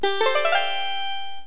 LevelUp.mp3